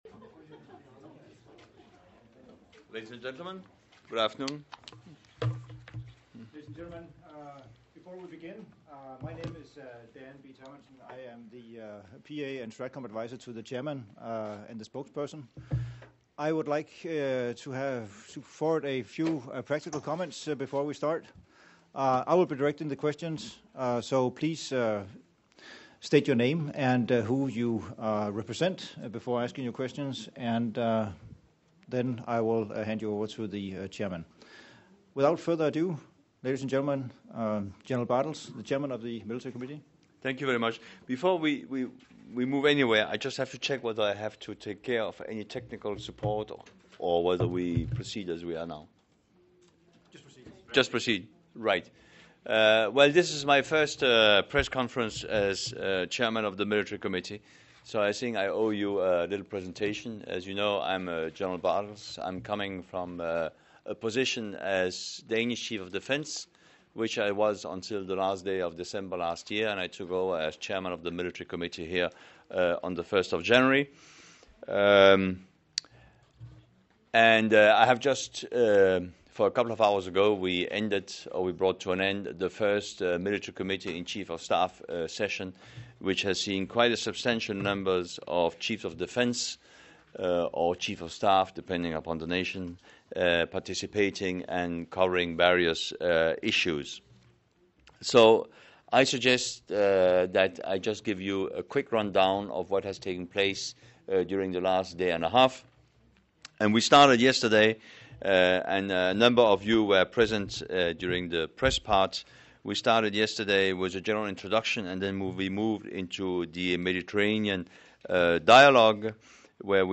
Press conference by General Knud Bartels, Chairman of the NATO Military Committee following the Meeting of NATO and Partner Chiefs of Defence